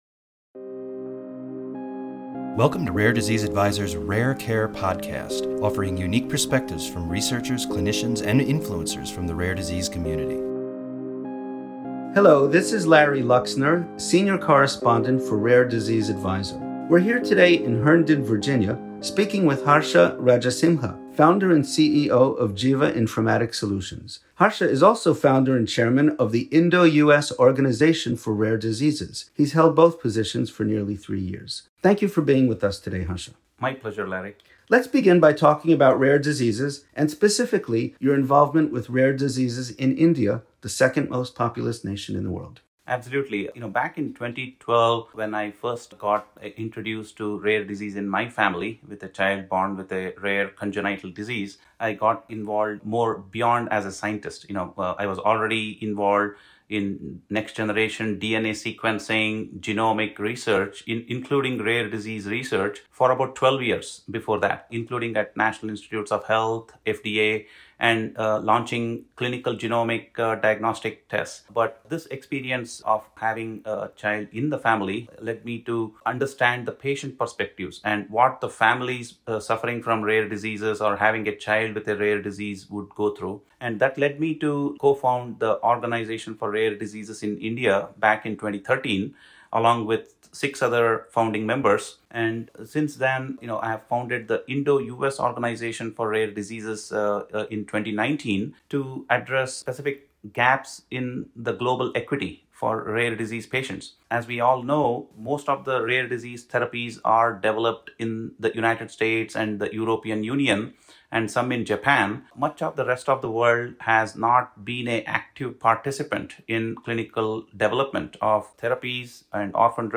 interviews
in Herndon, Virginia.